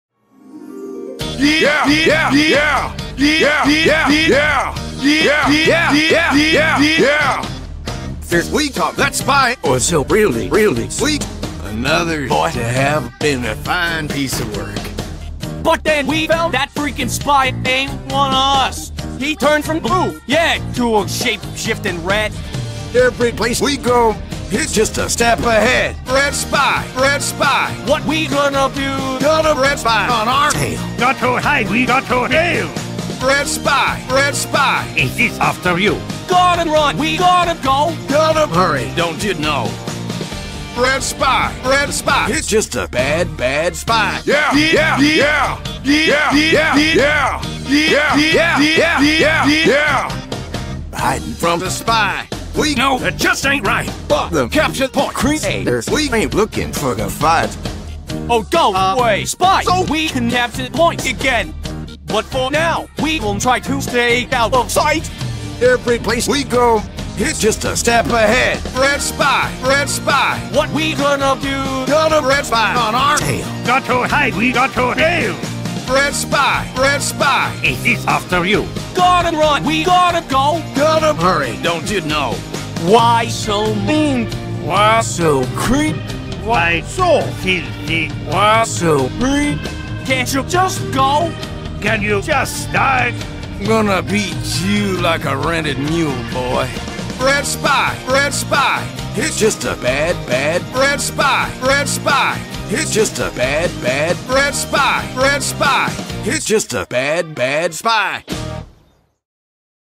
A rant song